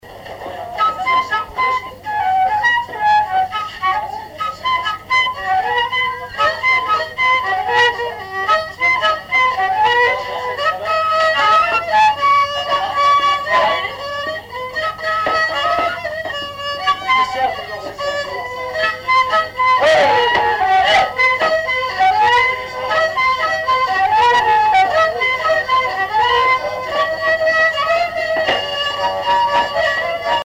danse : rat
Répertoire d'un bal folk par de jeunes musiciens locaux
Pièce musicale inédite